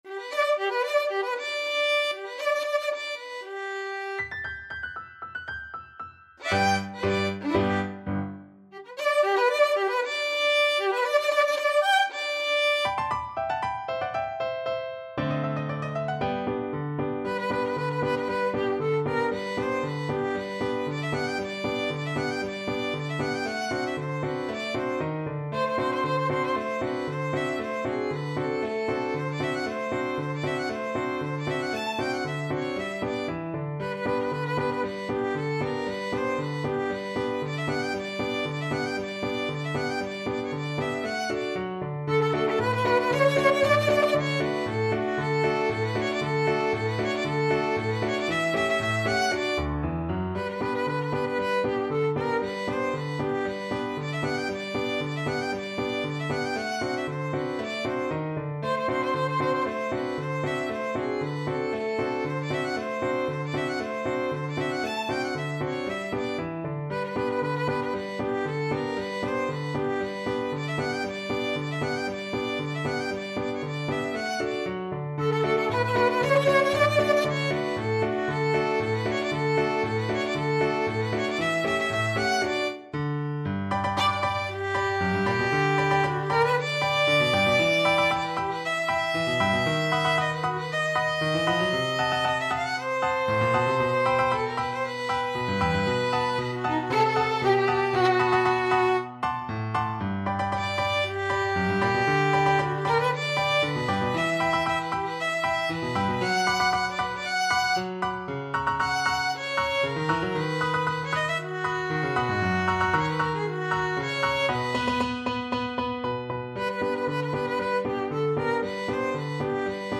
2/4 (View more 2/4 Music)
Tempo di Marcia (=116)
Classical (View more Classical Violin Music)